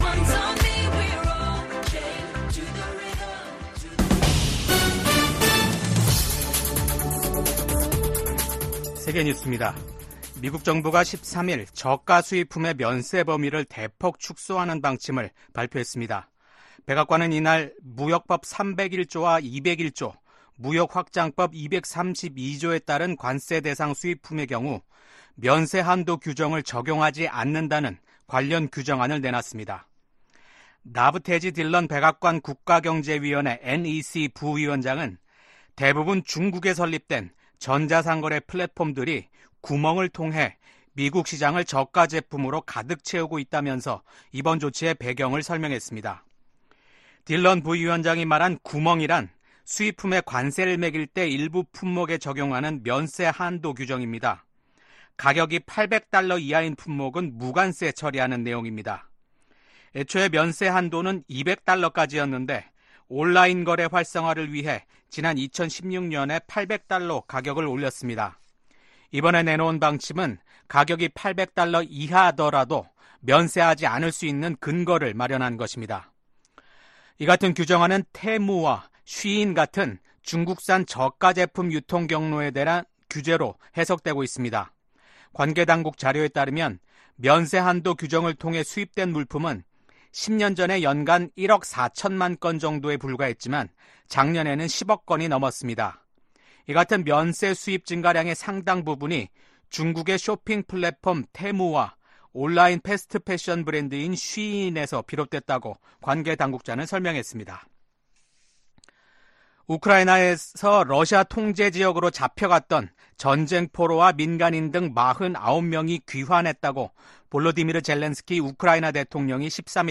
VOA 한국어 아침 뉴스 프로그램 '워싱턴 뉴스 광장' 2024년 9월 14일 방송입니다. 북한이 핵탄두를 만드는 데 쓰이는 고농축 우라늄(HEU) 제조시설을 처음 공개했습니다. 미국과 리투아니아가 인도태평양 지역에 대한 고위급 대화를 개최하고 러시아의 북한제 탄도미사일 사용을 비판했습니다.